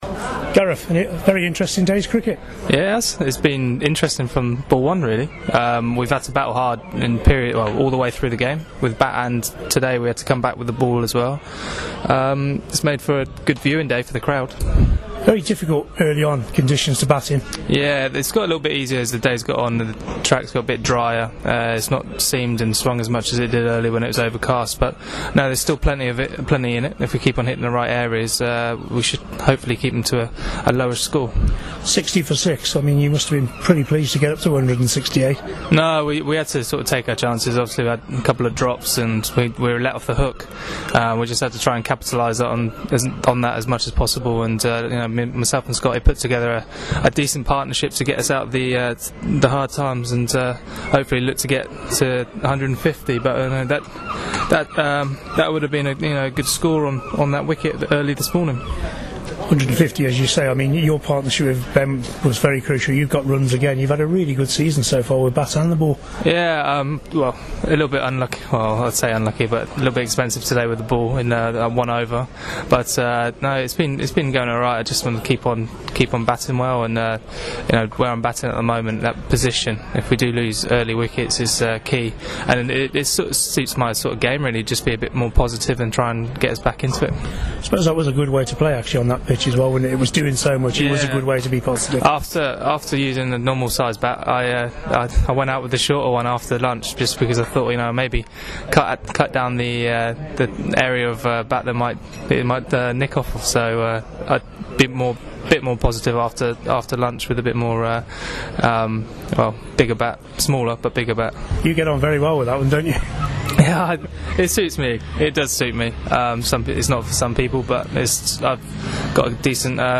after day one of the Championship match against Yorkshire at Scarborough.